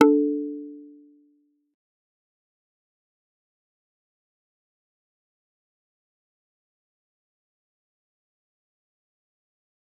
G_Kalimba-D4-mf.wav